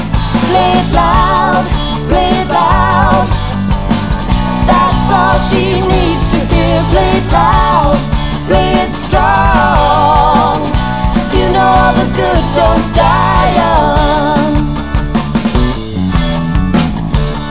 High energy explosive tunes with sweet harmonies.